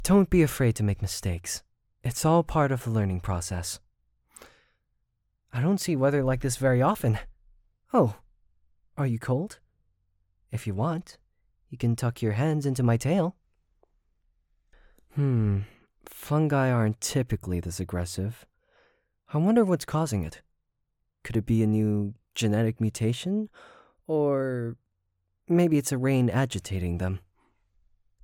Voice Actor
Voice: Medium to mid-deep pitched range, a bit sarcastic and also intelligent sounding.
• male young adult
• androgynous
• american